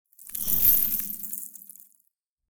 Free Frost Mage - SFX
ice_whoosh_16.wav